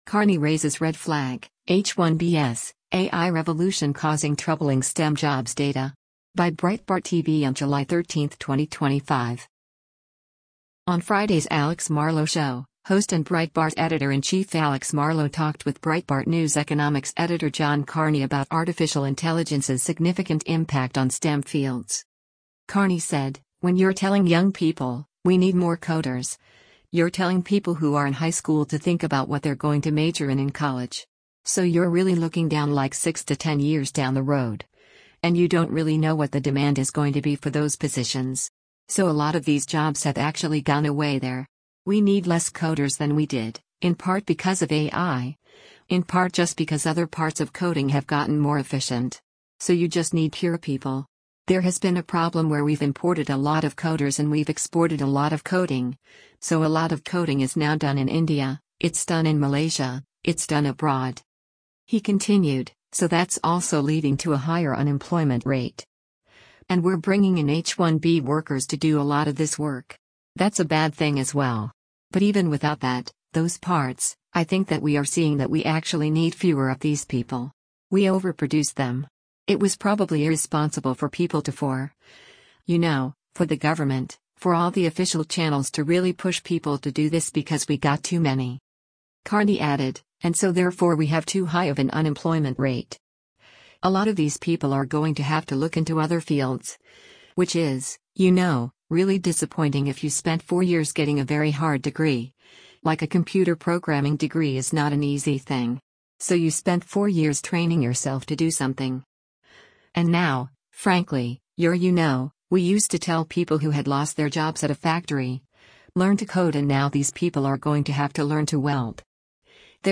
is a weekday podcast produced by Breitbart News and Salem Podcast Network.